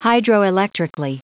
Prononciation du mot hydroelectrically en anglais (fichier audio)
hydroelectrically.wav